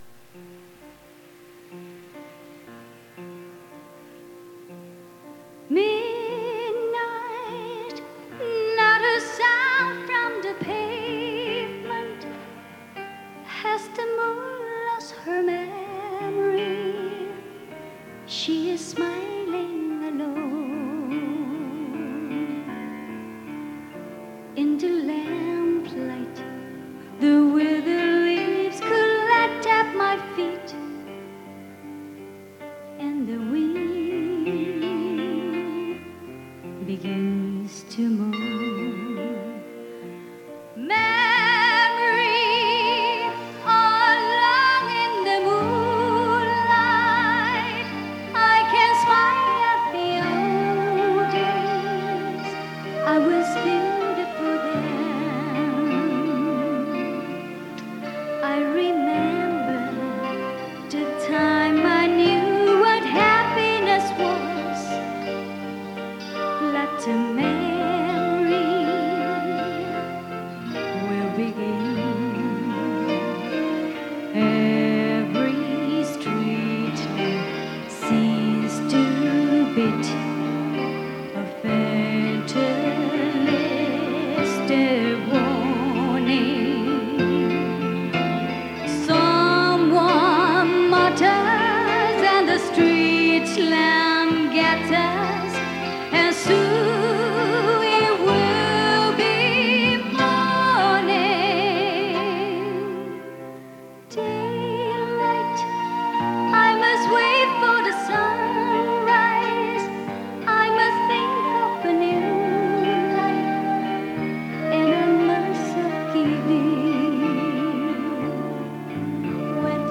粤语演唱：